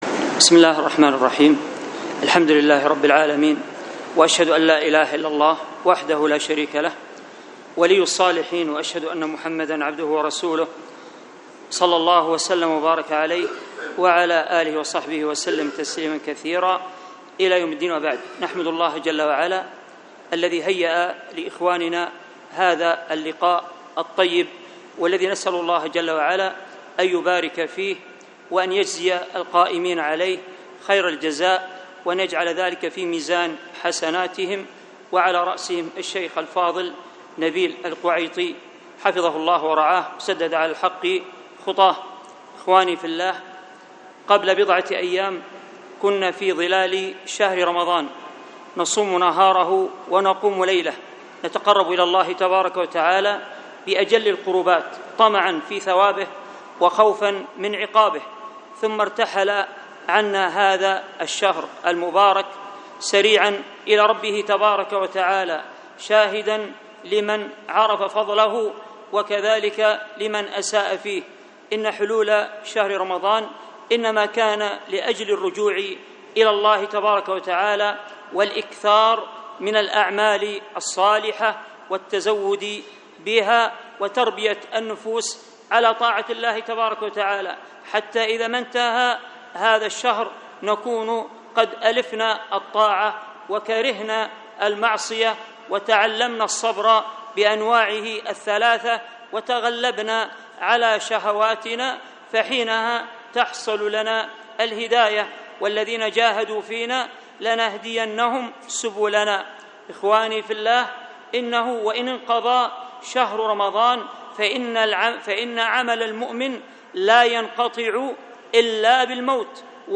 المحاضرة بعنوان أحكام زيارة القبور، وكانت بمسجد التقوى بدار الحديث بالشحر الجمعة ١٨ محرم ١٤٣٥هـ ألقاها